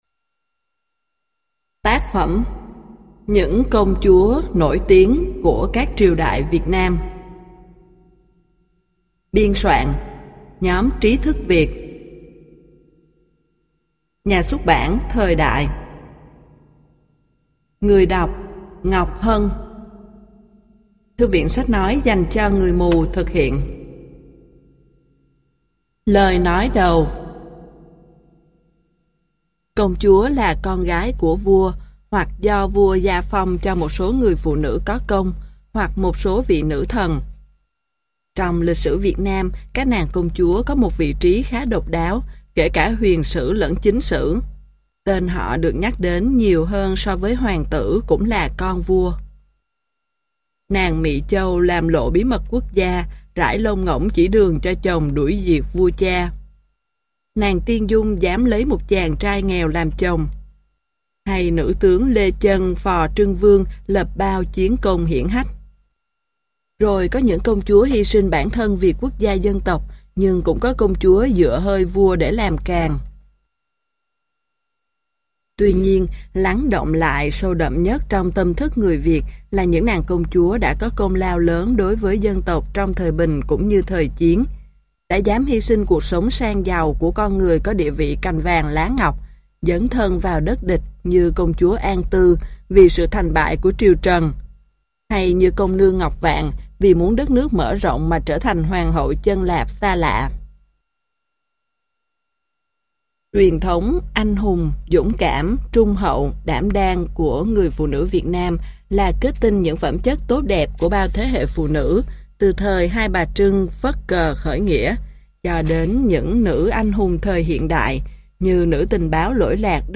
Sách nói Những Công Chúa Nổi Tiếng Của Các Triều Đại Việt Nam - Nhóm Trí Thức Việt - Sách Nói Online Hay